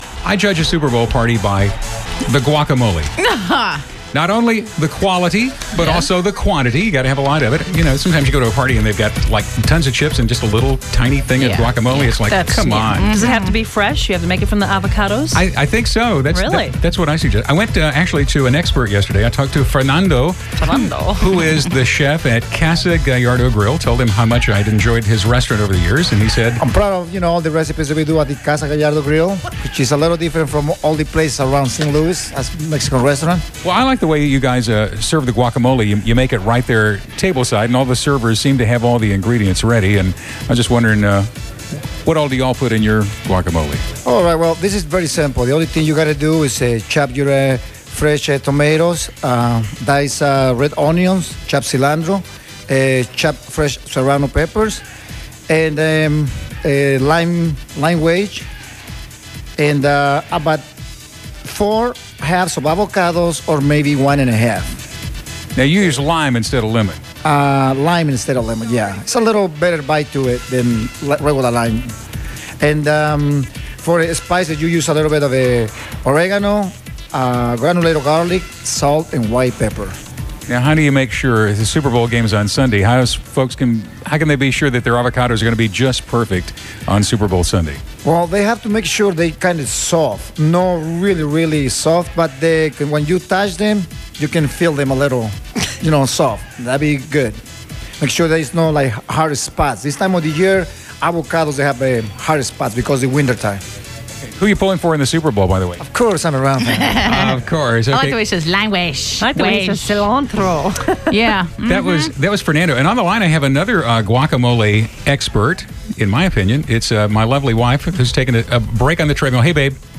We called her live, right in the middle of her morning trek on the treadmill.